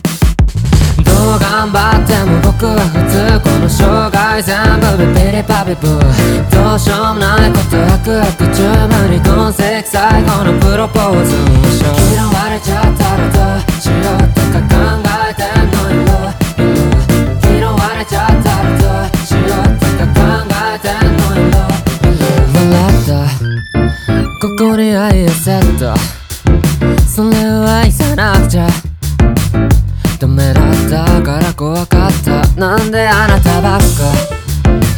J-Pop
2025-06-06 Жанр: Поп музыка Длительность